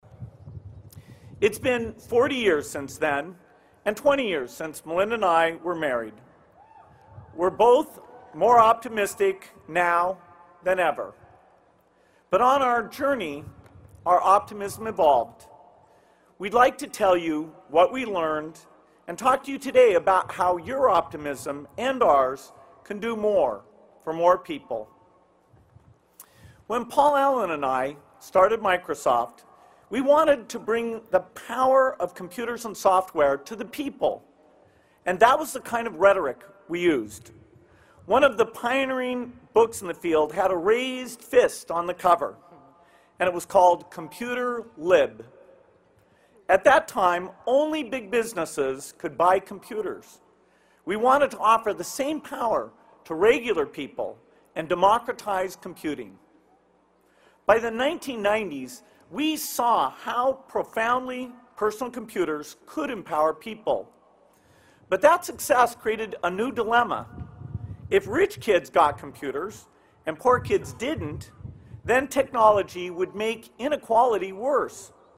公众人物毕业演讲第23期:比尔盖茨夫妇于斯坦福大学(4) 听力文件下载—在线英语听力室